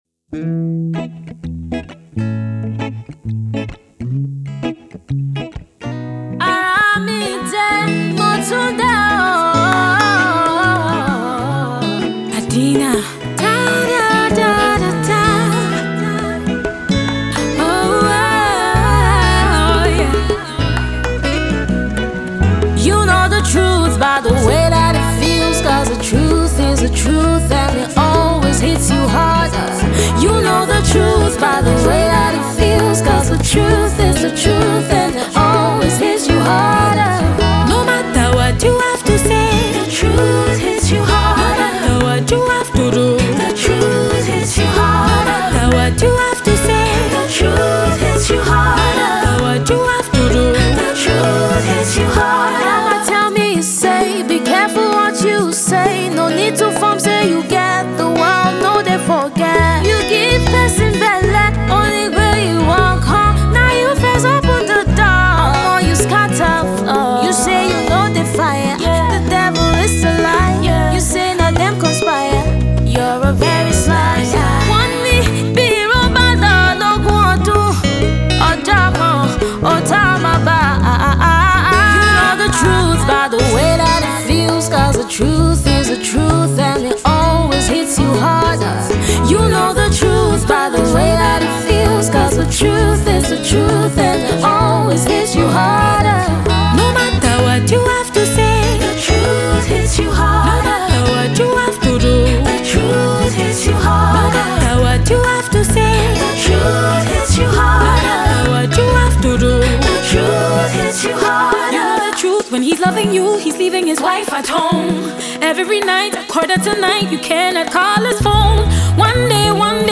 Award-wining Nigerian Afro-Soul Queen
Afro-pop star
equally distinctive vocals